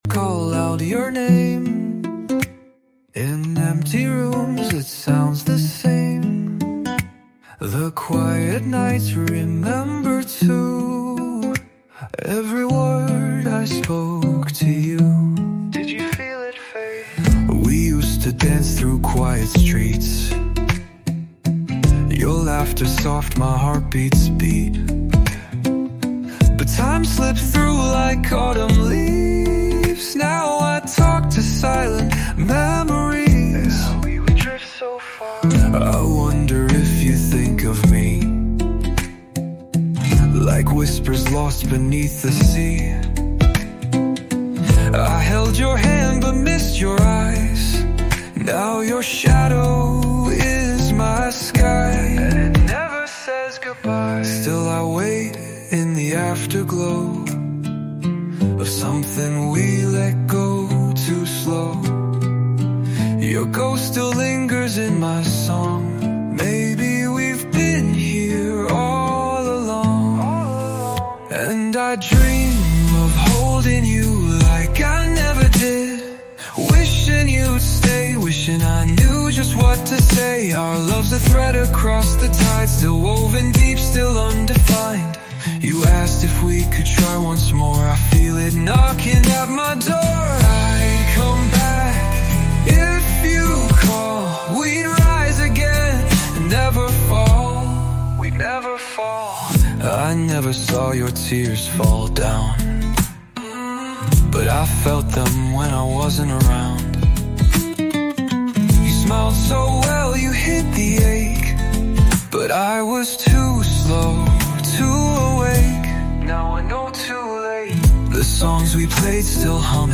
Chill Lo-fi Beats for Focus and Late Night Work